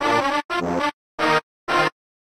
hey every Meme Sound Effect